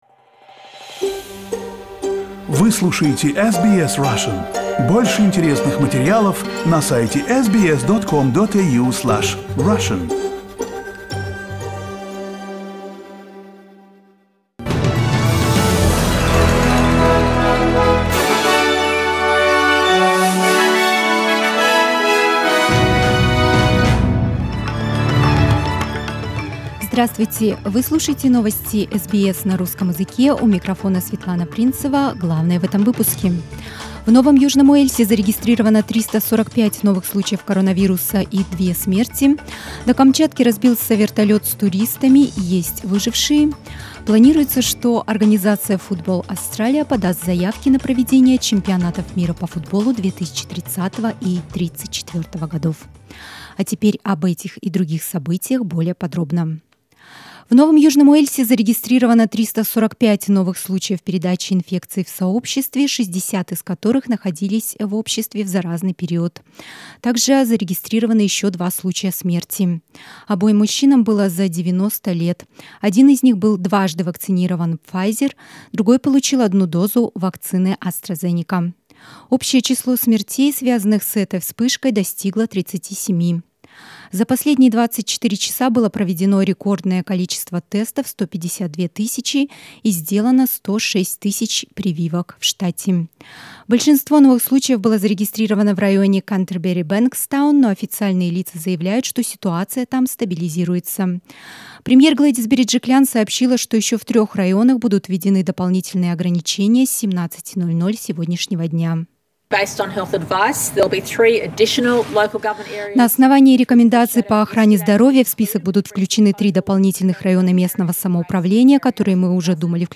Новости SBS на русском языке - 12.08